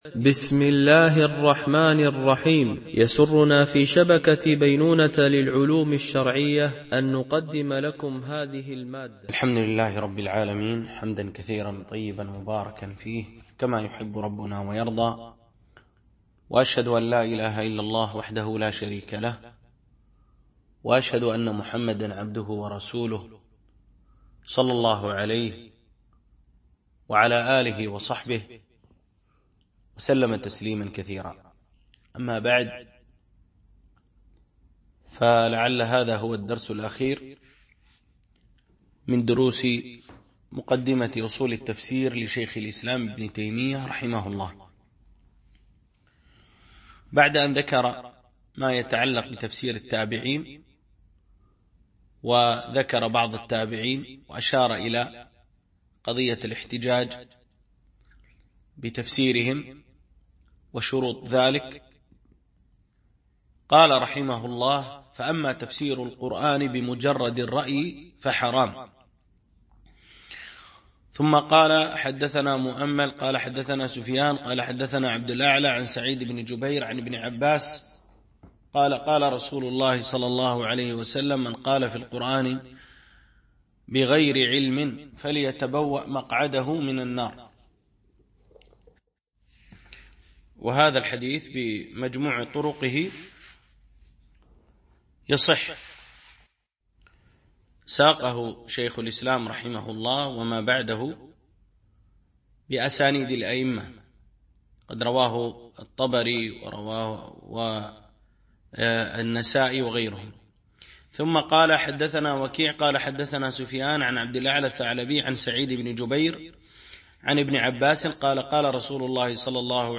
شرح مقدمة في أصول التفسير ـ الدرس 12